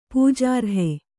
♪ pūjārhe